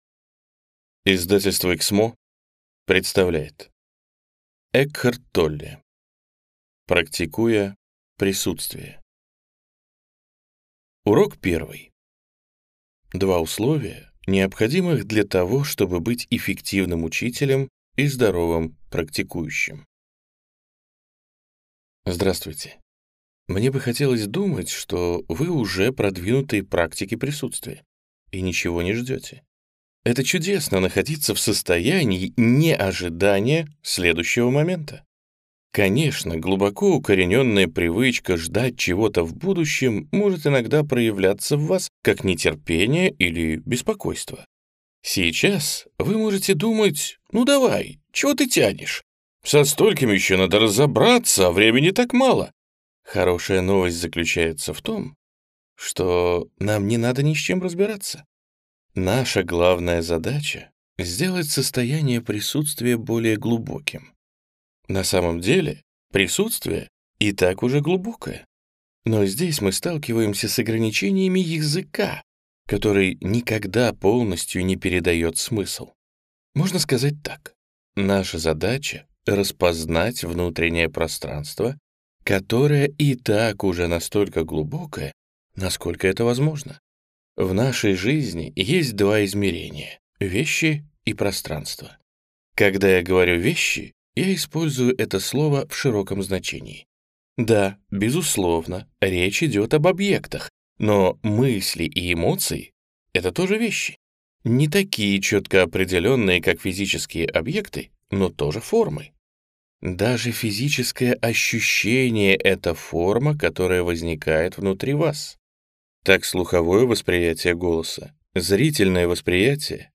Аудиокнига Практикуя присутствие | Библиотека аудиокниг